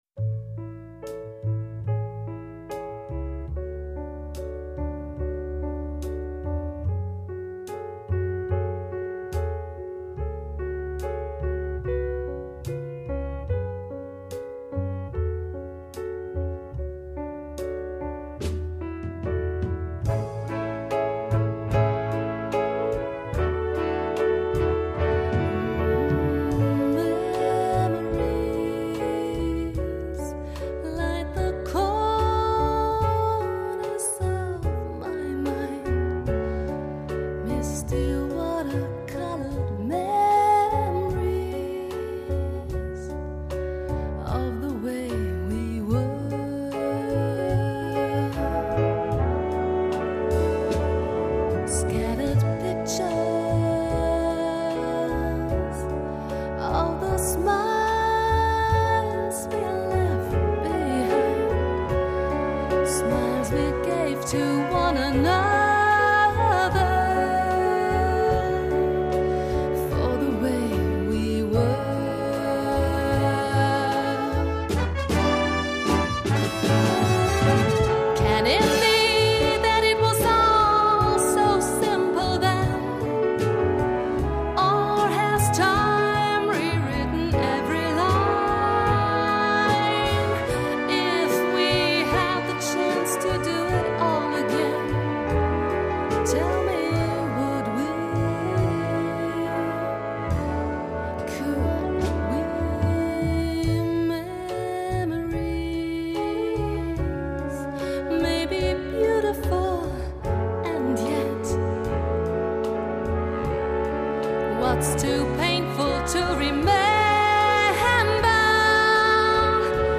sehr variabel
Vocals (Gesang)